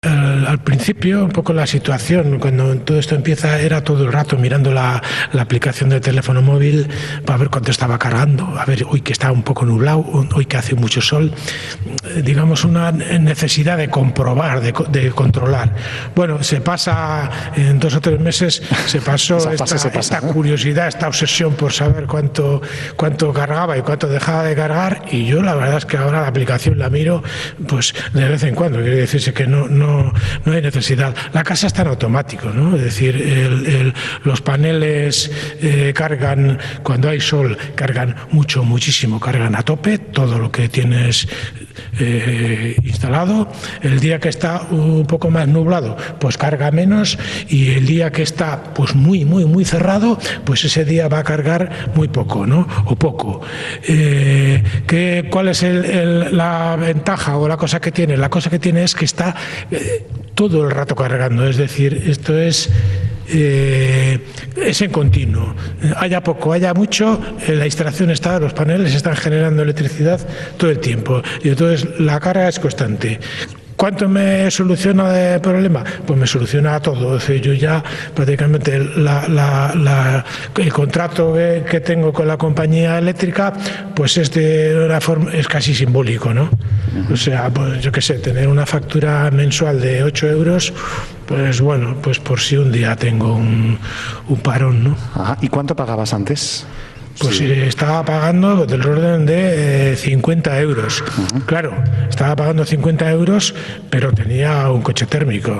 Onda Vasca ha emitido un programa especial desde una casa equipada con energía solar fotovoltaica capaz de autoabastecer el consumo del hogar y de un vehículo eléctrico